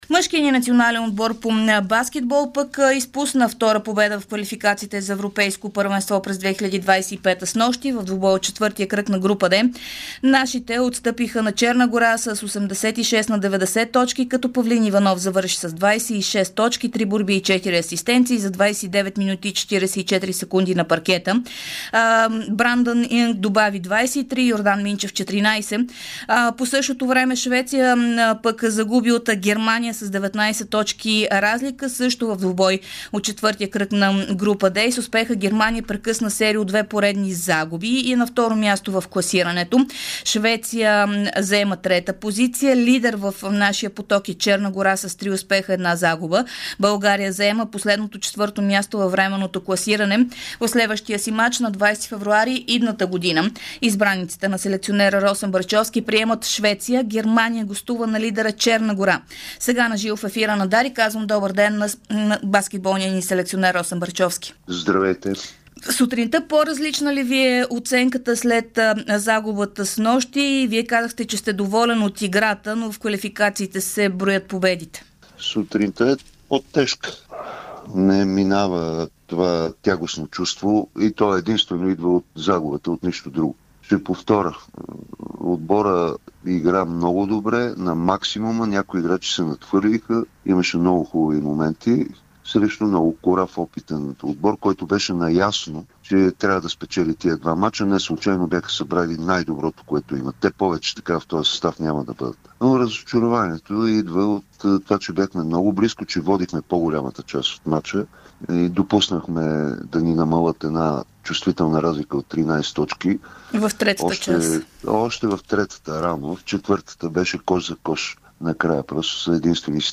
говори пред Дарик радио и dsport в деня след загубата с 86:90 от Черна гора в квалификационен мач за Евробаскет 2025.